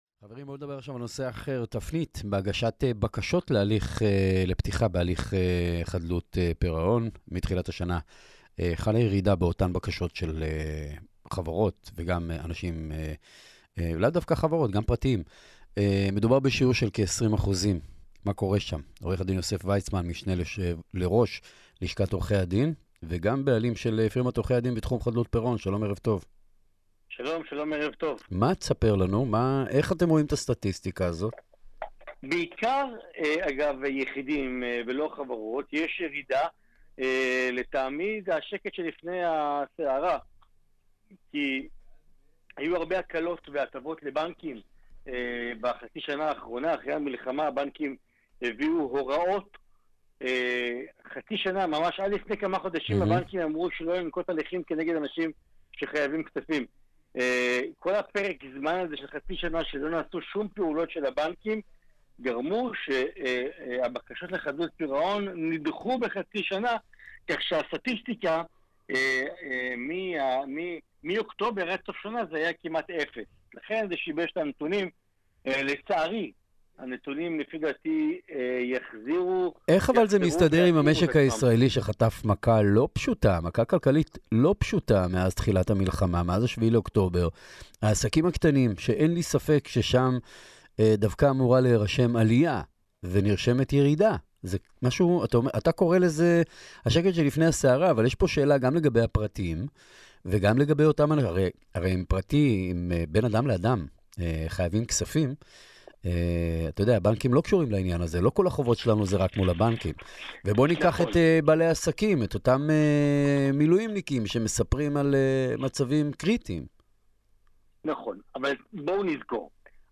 בראיון על תיקי חדלות פירעון בתקופת המלחמה